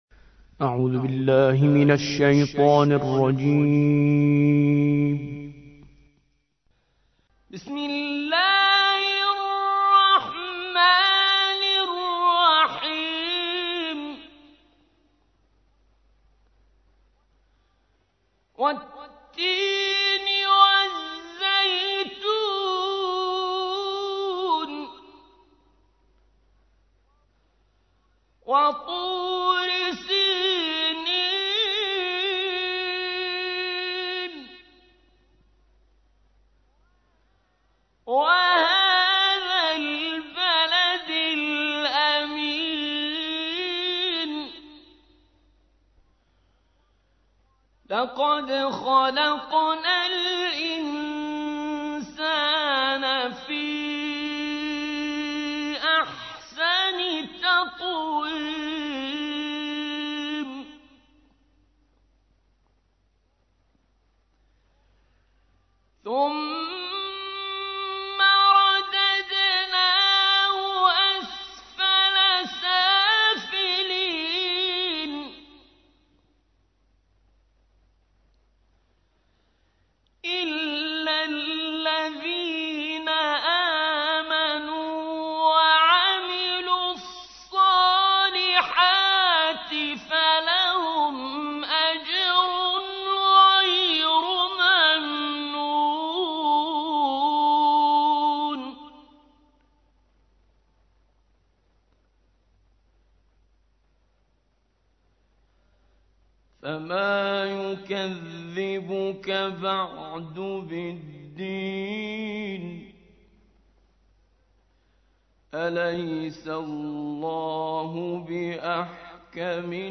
سورة التين / القارئ